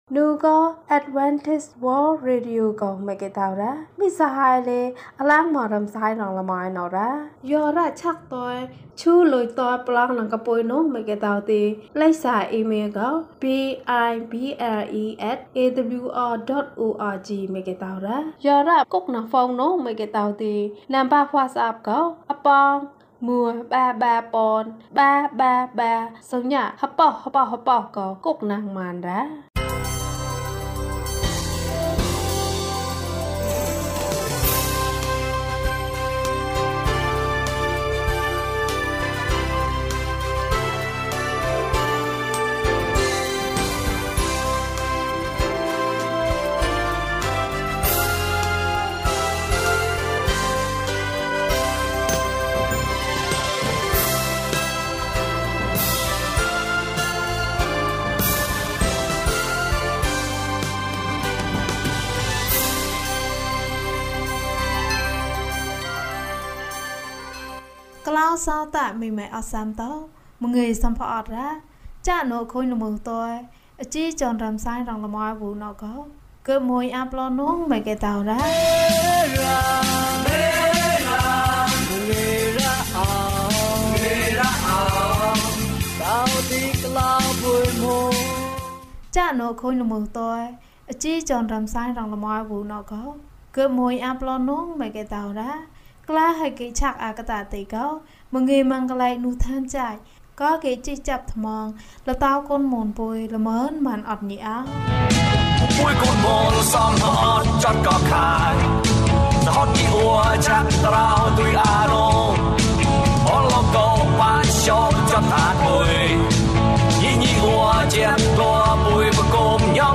ရှင်ဘုရင်။ အပိုင်း ၁ ကျန်းမာခြင်းအကြောင်းအရာ။ ဓမ္မသီချင်း။ တရားဒေသနာ။